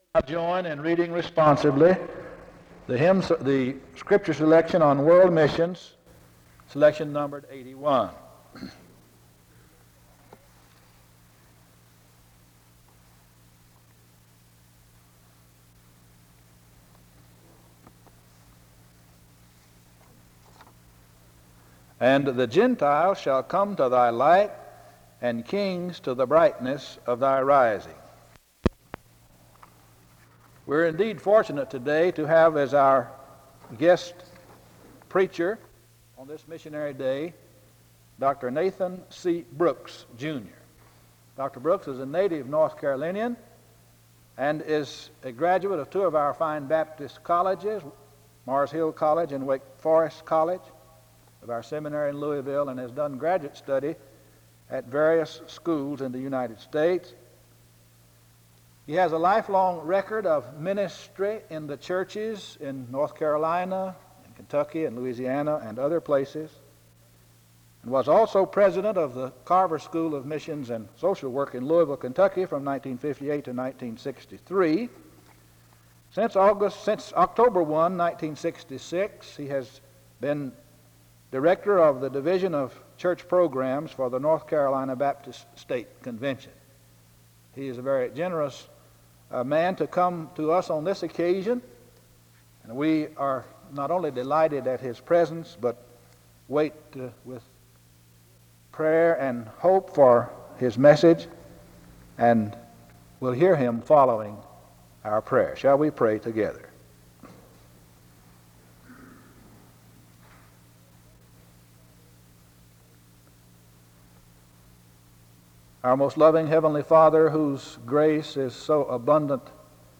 The service begins with an introduction from 0:30-1:46. A prayer is offered from 1:55-4:19.